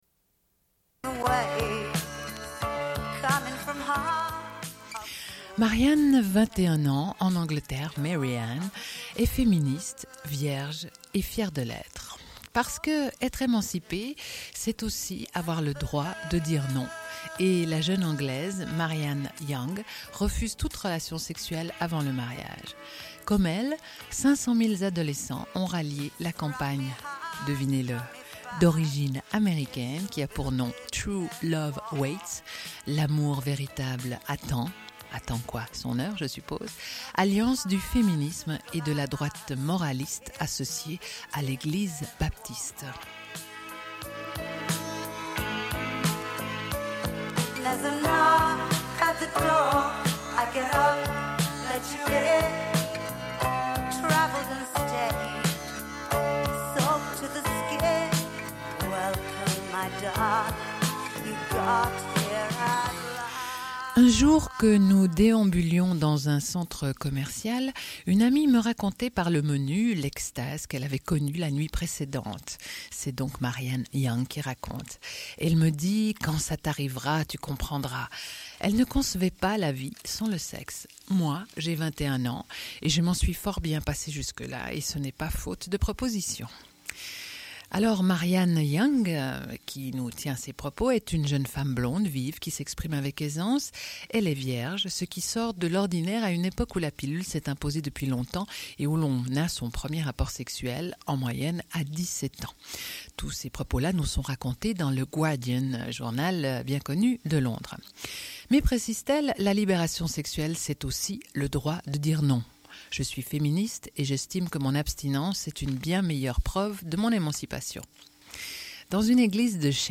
Suite de l'émission : revue de presse « Planète femmes », lecture d'articles relatant la situation des femmes dans les pays ou régions suivantes : Chiapas, Italie, Grande-Bretagne, Algérie, Thaïlande, Égypte, Pakistan, Allemagne.